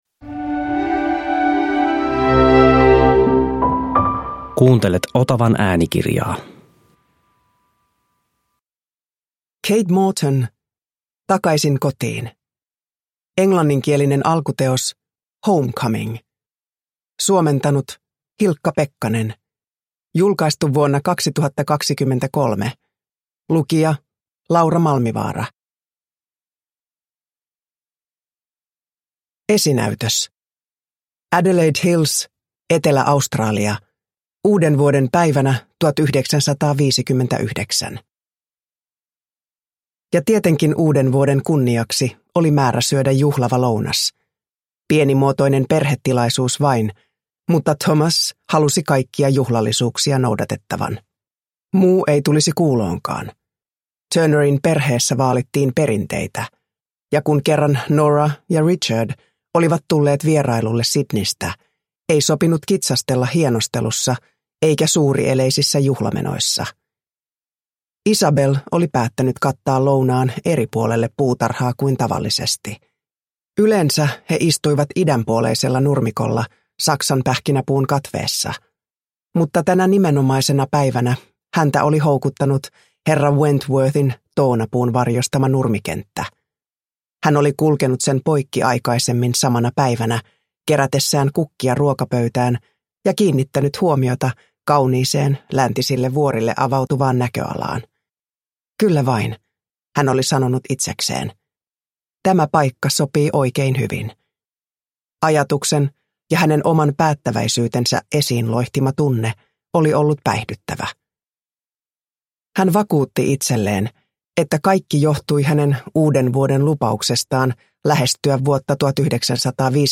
Takaisin kotiin – Ljudbok – Laddas ner
Uppläsare: Laura Malmivaara